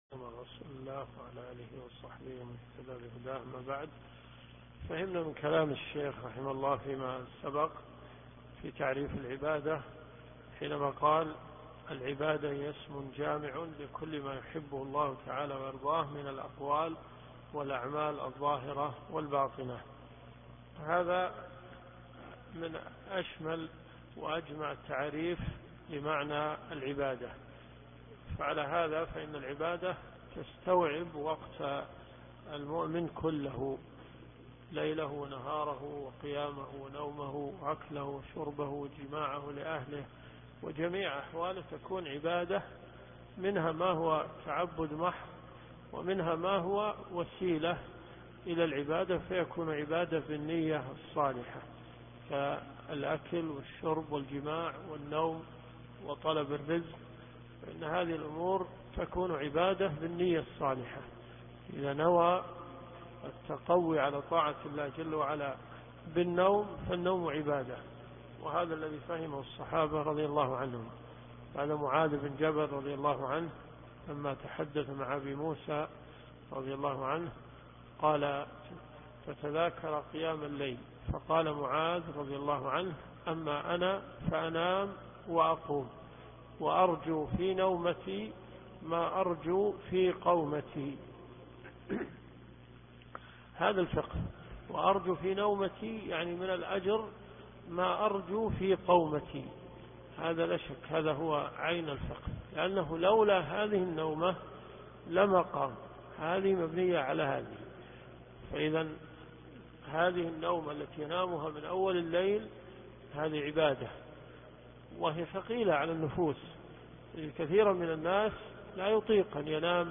دروس صوتيه